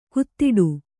♪ kuttiḍu